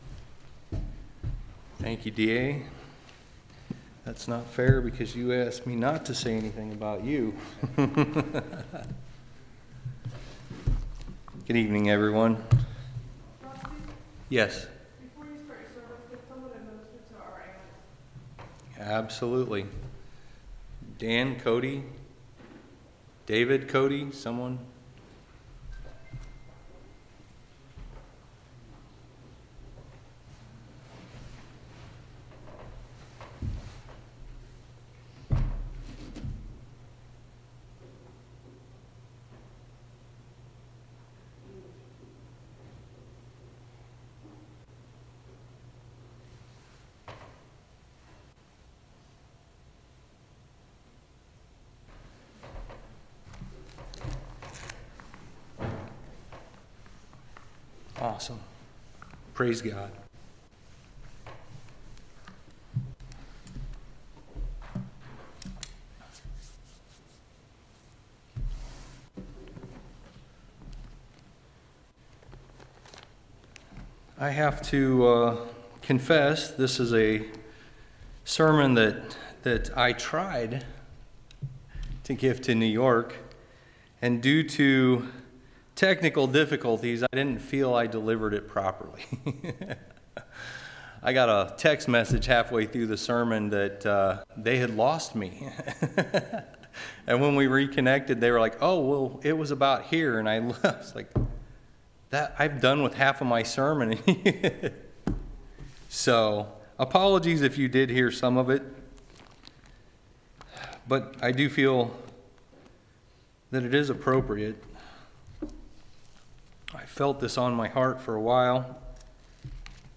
1/23/2022 Location: Temple Lot Local Event